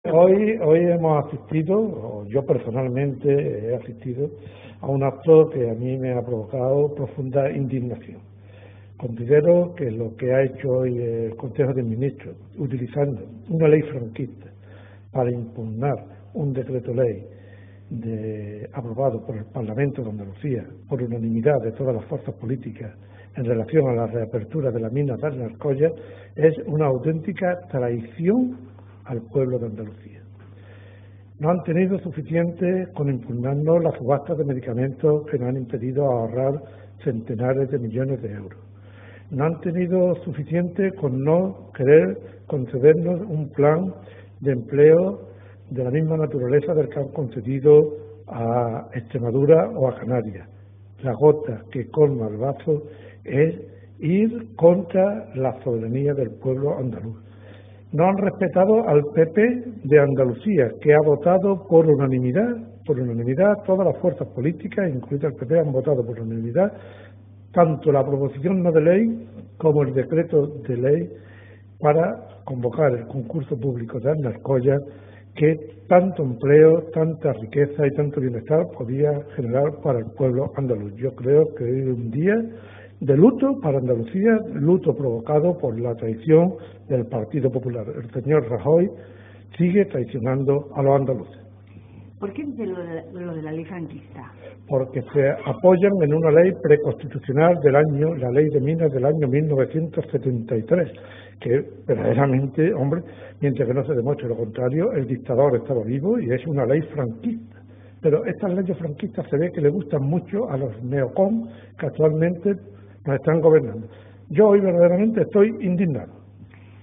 Descargar archivo de audio declaraciones del Consejero